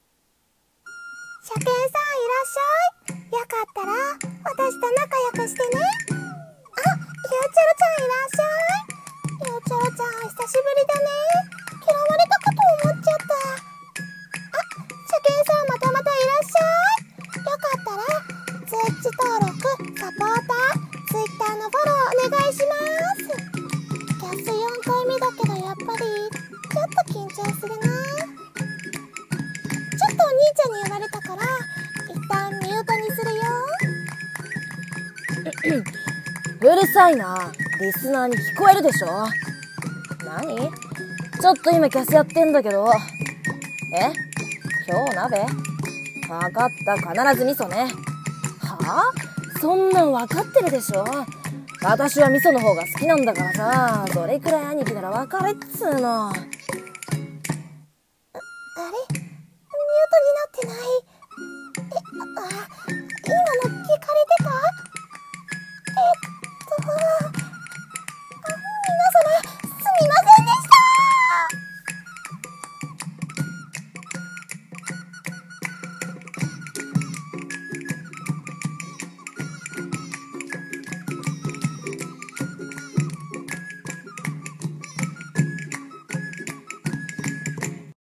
【声劇】萌え声が地声バレる【1人声劇】